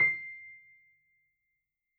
piano_084.wav